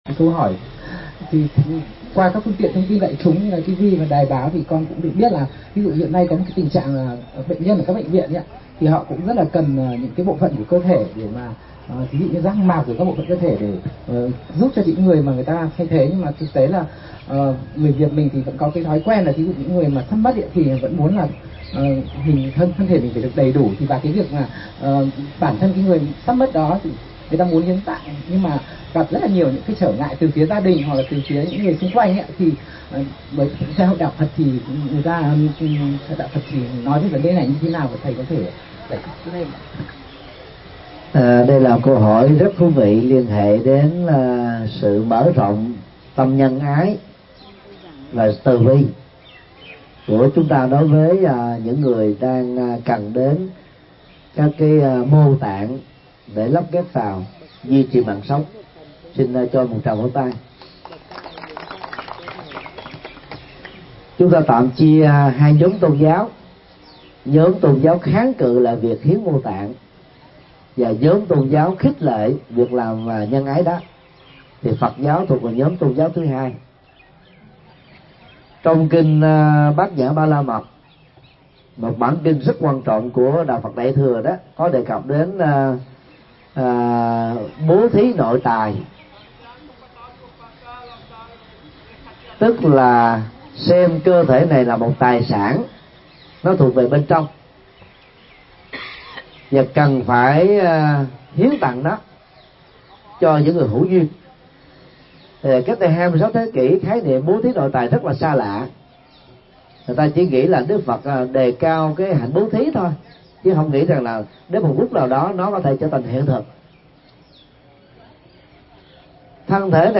Vấn đáp: Có nên hiến mô tạng sau khi chết – Thích Nhật Từ